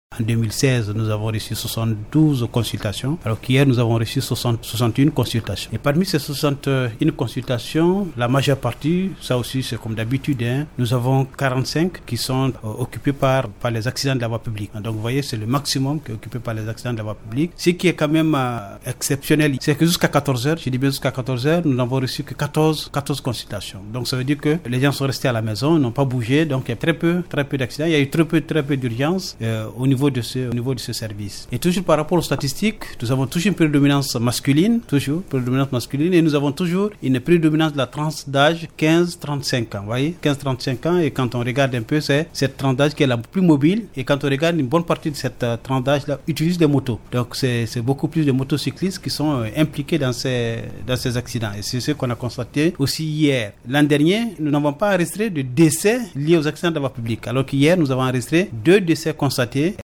Pour cet habitant de Diondiori, la Tabaski a été célébrée avec sobriété…